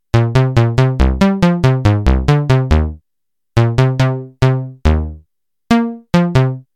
The program I implemented essentially “evolves” a simple musical grammar based on the user’s tastes and renders the sounds using a synthesized bass patch.
The first 3 examples sound similar because they are based on the same grammar derived during one run.